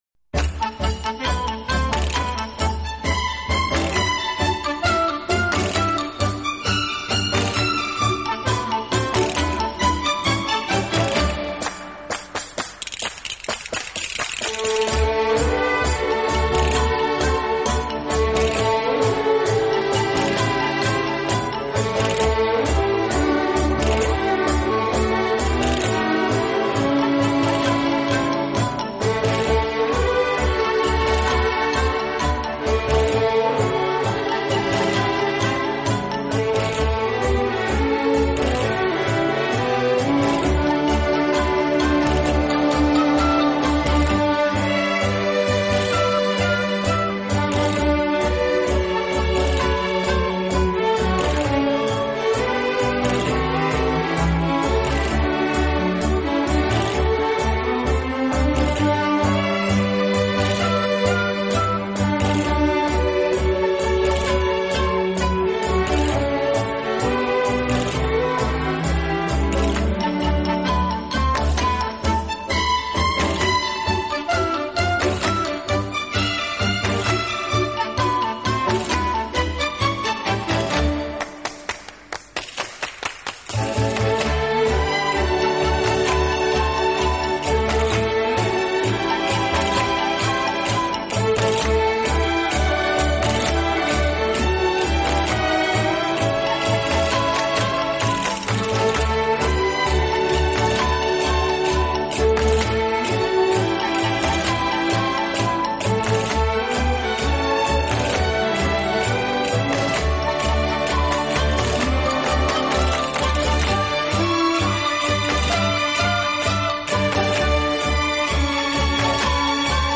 【轻音乐专辑】
3、乐队演奏以华丽著称，气势磅礴。